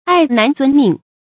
礙難遵命 注音： 讀音讀法： 意思解釋： 礙：妨礙；遵：遵從。